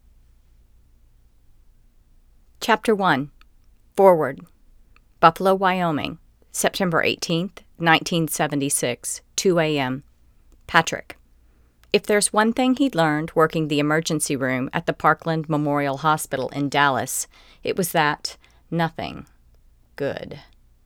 Here’s one trying the towel/book method, further from the pop filter (yep, I have the tennis racket), and with audacity recording volume turned up a bit.
Overall much better. Plain, ordinary noise now, no compressors or motors.
I still hear it (popping) though